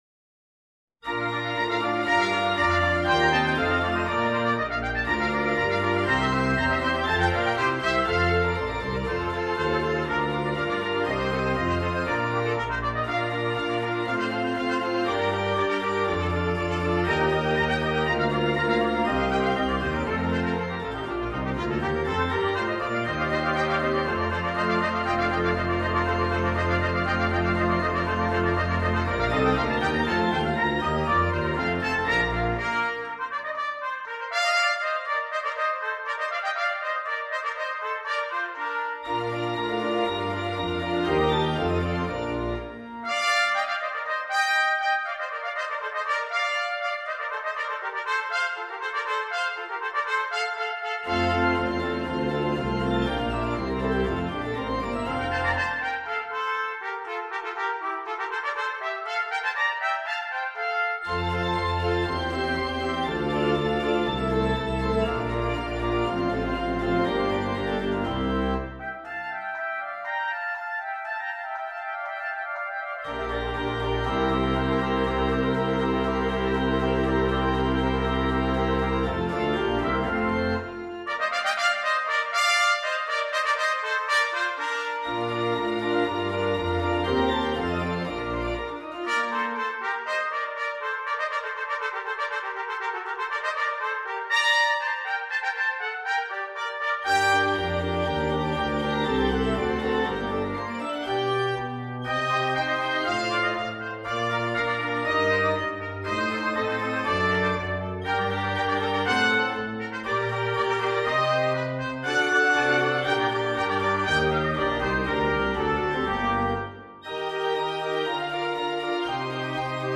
Dúo
con el órgano
Trompeta do (Dúo), Trompeta Piccolo & Trompeta mib (Dúo)
Clásicas
Acompañamiento de piano